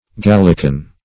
Gallican \Gal"li*can\, a. [L. Gallicanus: cf. F. gallican.]